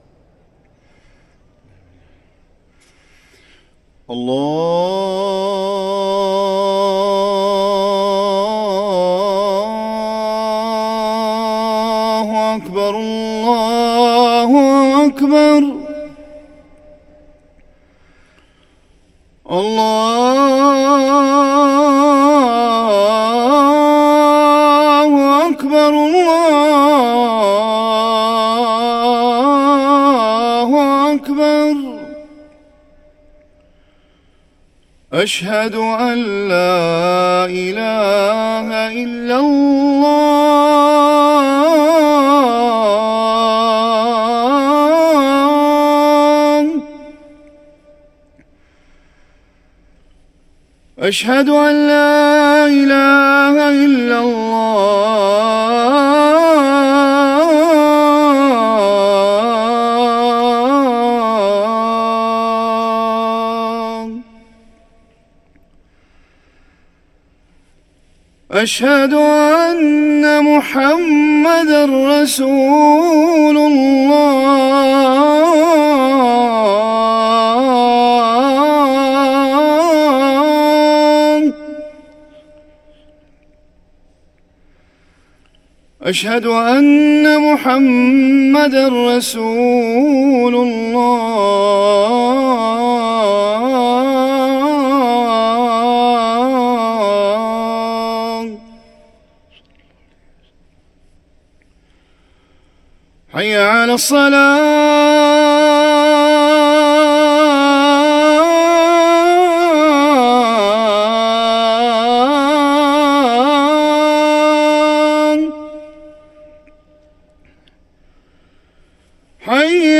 أذان الفجر